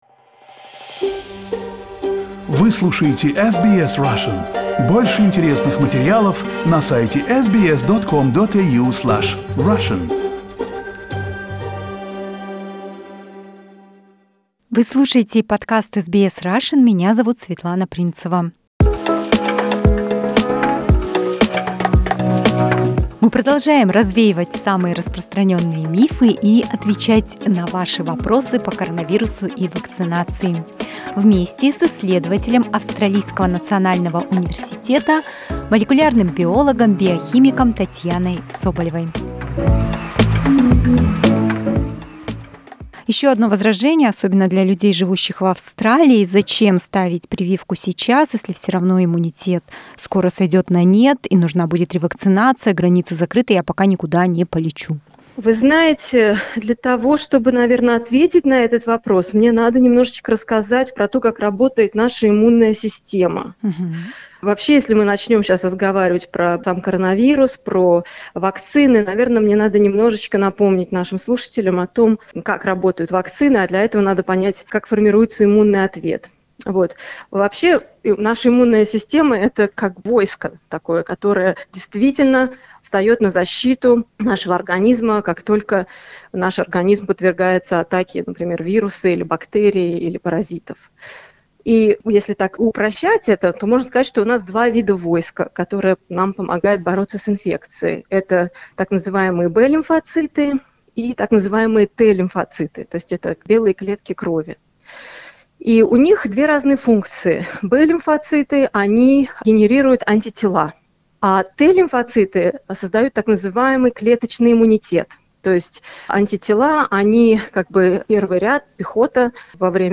Australian scientists are answering the questions from our listeners about coronavirus and COVID-19 vaccination.